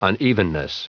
Prononciation du mot unevenness en anglais (fichier audio)
Prononciation du mot : unevenness